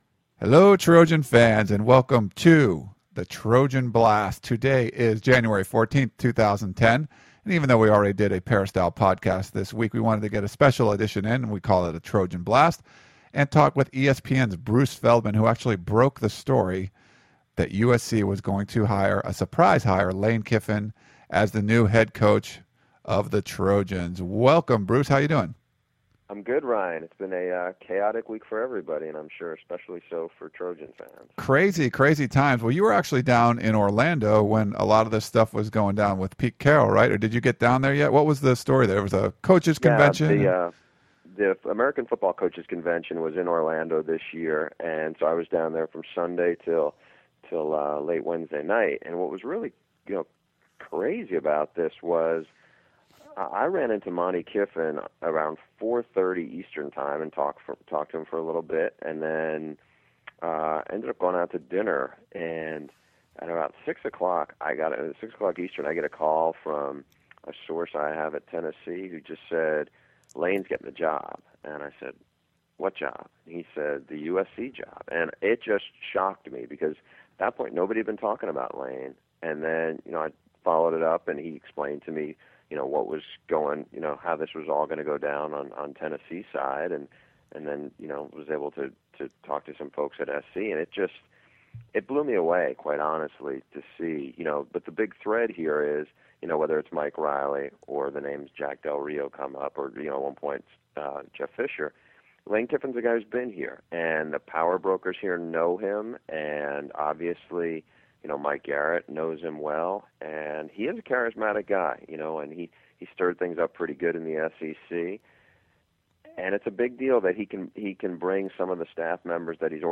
College football reporter Bruce Feldman broke the story of Lane Kiffin accepting the head coaching position at USC. In this Trojan Blast we talk to Feldman about how this shocking hire went down, what the reaction has been so far, the return of Ed Orgeron and some of the recent controversy surrounding this hire.